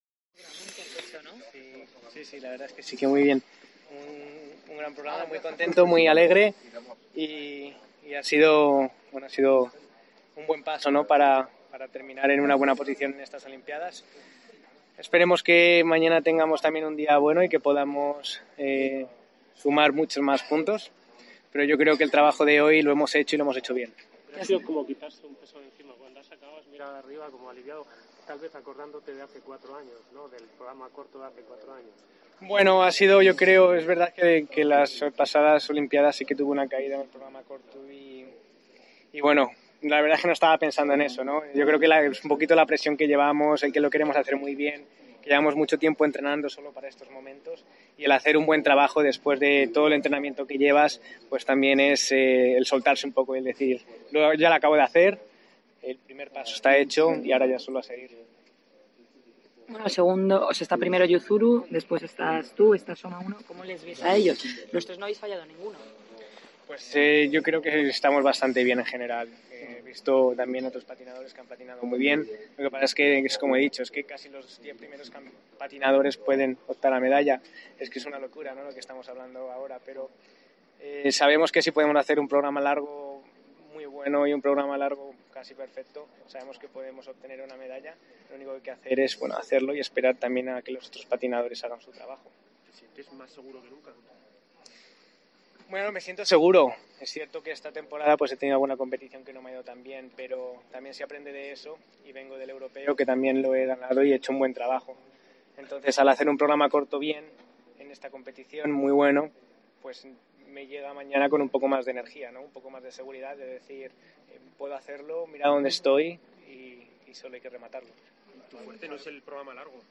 El patinador atendió a los medios tras concluir su brillante ejercicio en el programa corto.